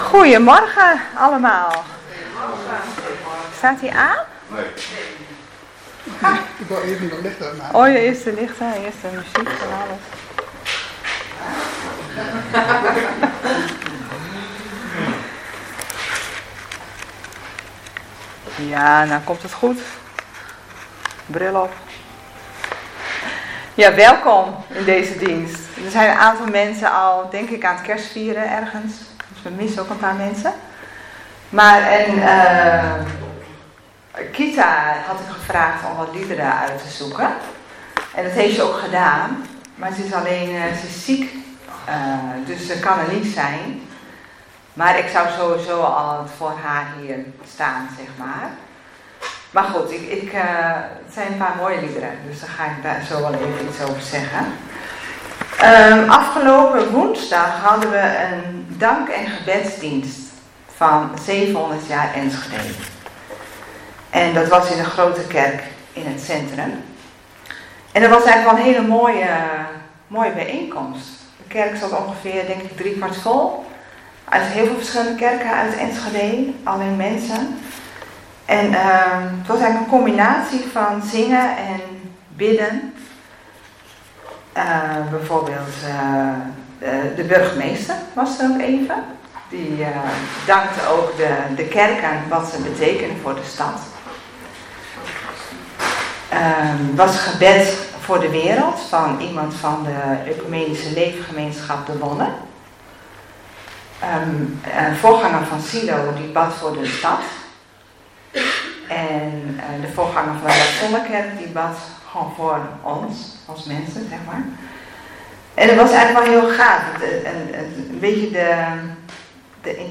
21 december 2025 dienst - Volle Evangelie Gemeente Enschede
Preek